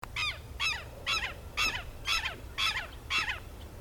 Tero Común (Vanellus chilensis)
Nombre en inglés: Southern Lapwing
Fase de la vida: Adulto
Provincia / Departamento: Entre Ríos
Localidad o área protegida: Ceibas
Condición: Silvestre
Certeza: Observada, Vocalización Grabada